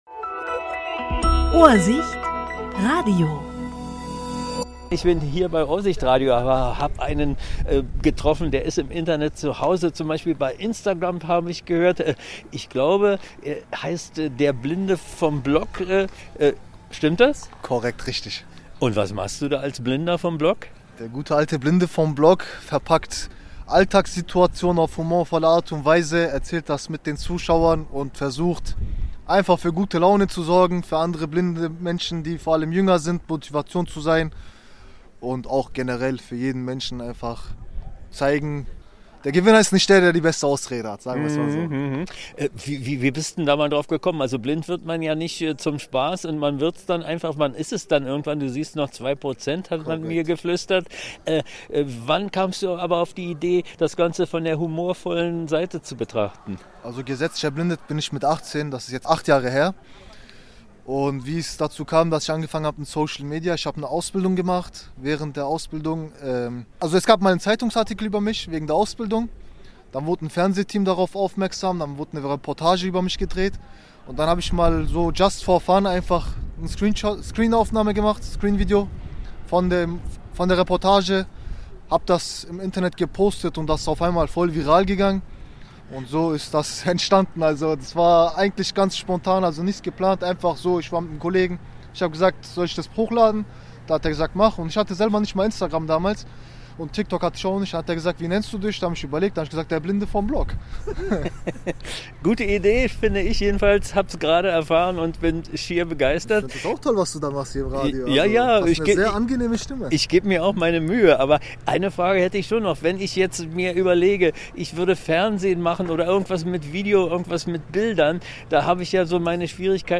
Interview 27.10.2025: Der Blinde vom Block